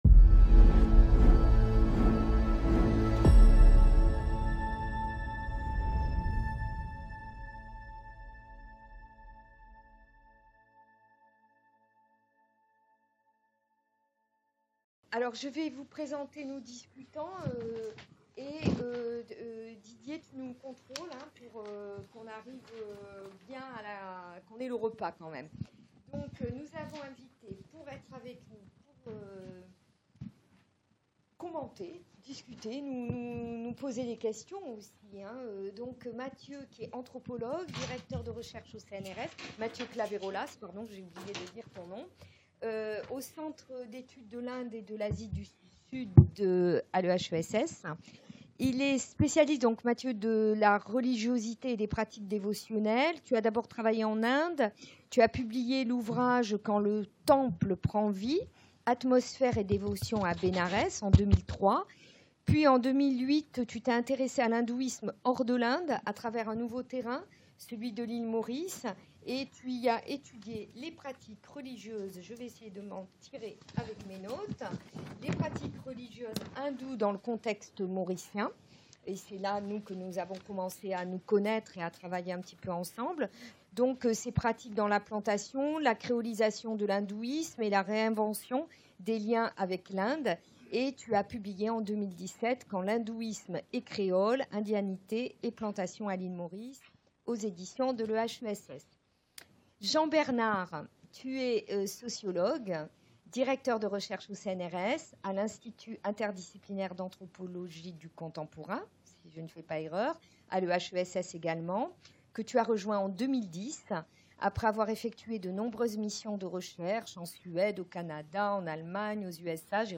Première discussion générale | Canal U
Mémoire en strates - Journée d’études Mondes Caraïbes et Transatlantiques en Mouvement | Mercredi 22 mai